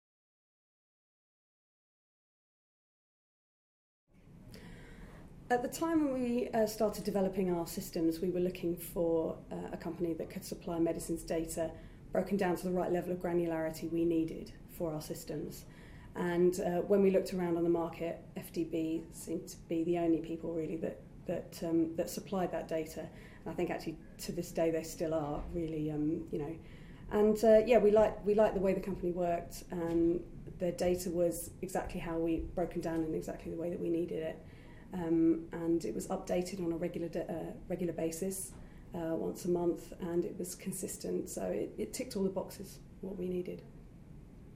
Podcast interview with UCLH - Part 1